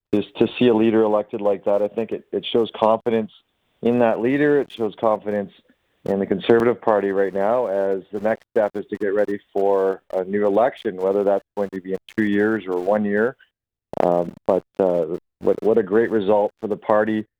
Ryan Williams, Conservative MP for the Bay of Quinte says the large majority Poilievre won is a good sign.